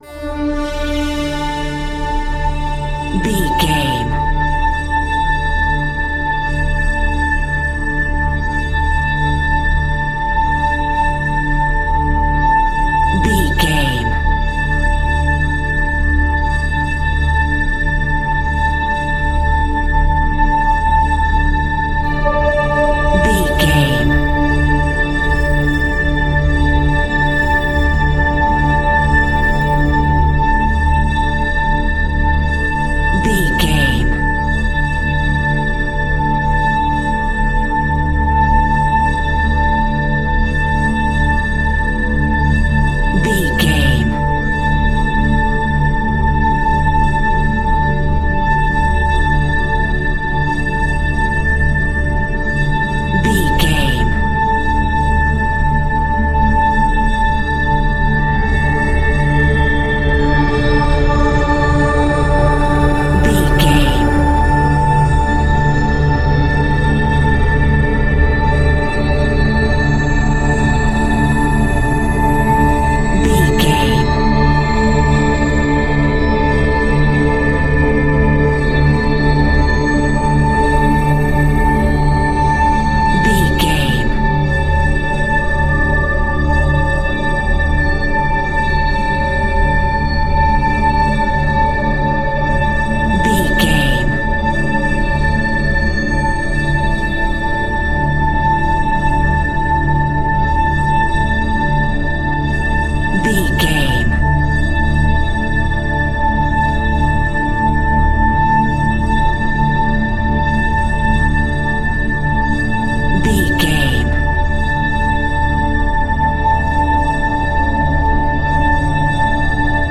Aeolian/Minor
Slow
ominous
haunting
eerie
ethereal
synthesiser
Synth Pads
atmospheres